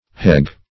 hegge - definition of hegge - synonyms, pronunciation, spelling from Free Dictionary Search Result for " hegge" : The Collaborative International Dictionary of English v.0.48: Hegge \Heg"ge\, n. A hedge.